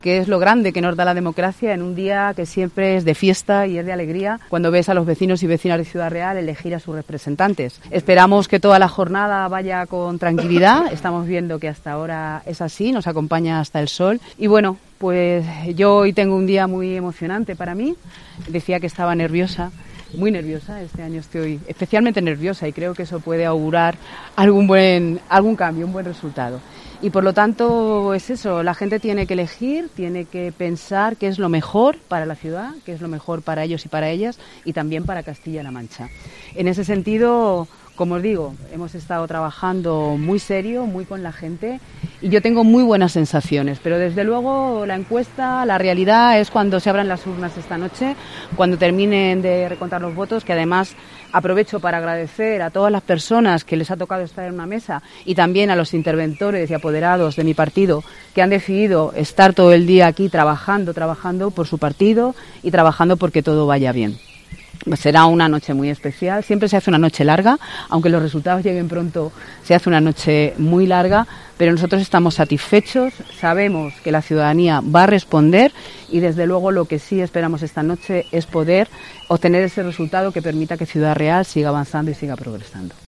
La candidata socialista a la Alcaldía Pilar Zamora ejercía su derecho al voto en el Centro Cívico del barrio de Los Rosales
Así lo manifestaba momentos después de ejercer su derecho al voto en el Centro Cívico del Barrio de los Rosales.